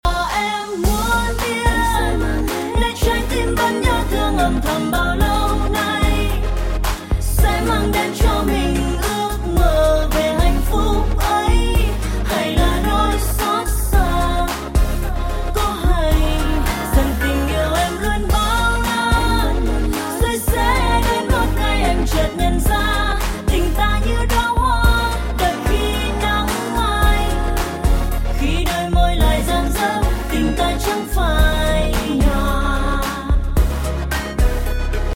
Thể loại nhạc chuông: Nhạc trẻ HOT